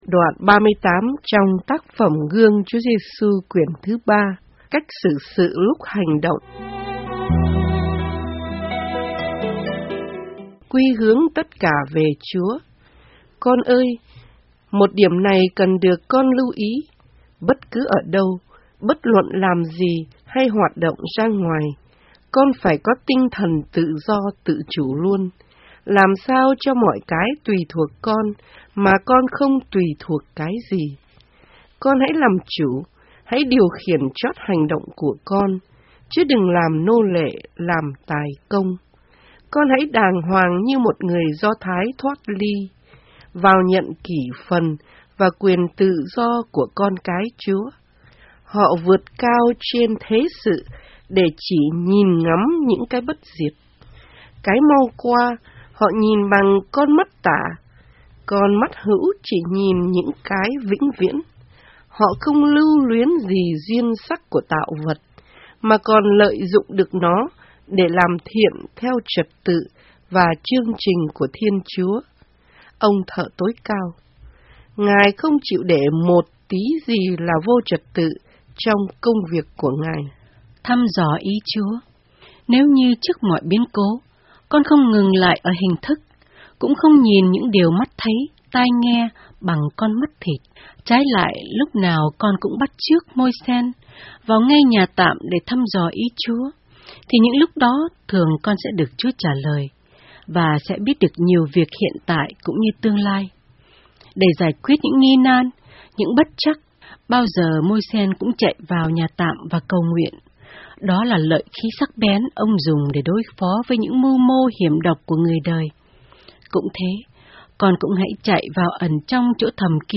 Audio Book Guong Chua Giesu Cuon 3 :: Memaria